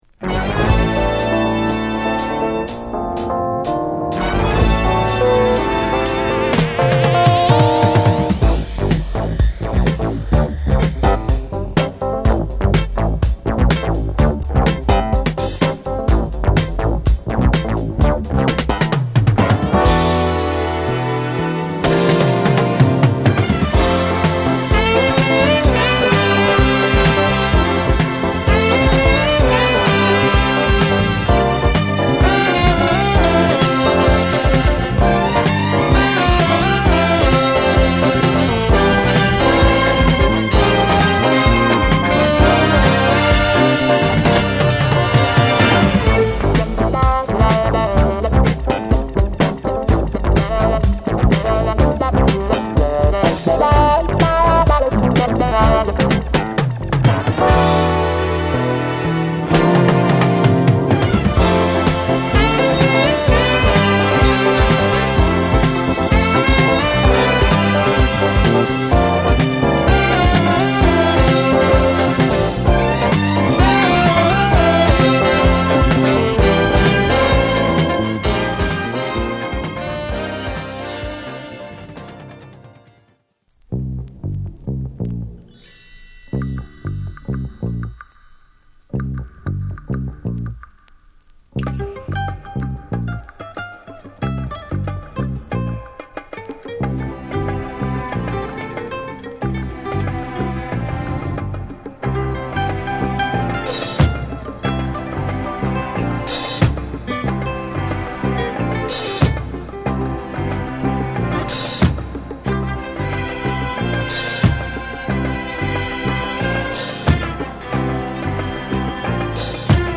中盤以降のシンセのソロを中心とした展開もカッコいい！